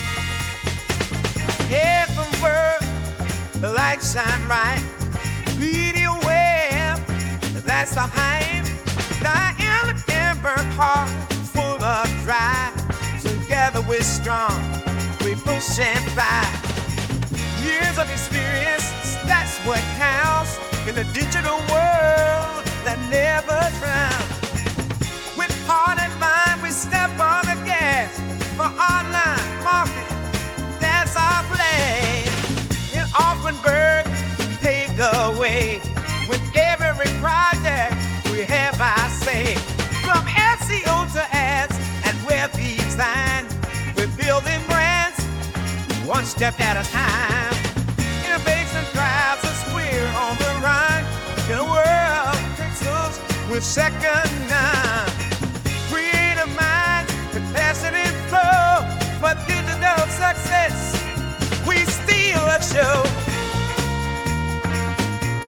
Suggestions: R&B, Soul